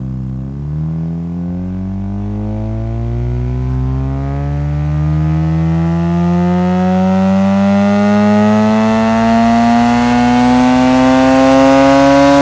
rr3-assets/files/.depot/audio/Vehicles/wr_02/wr_02_accel.wav